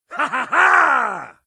laughter_06